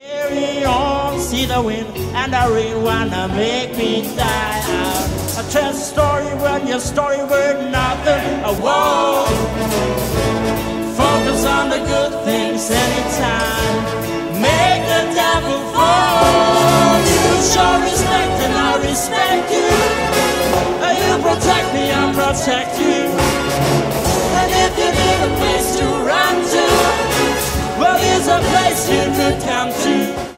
Gattung: Solo für Gesang und Symphonisches Blasorchester
Besetzung: Blasorchester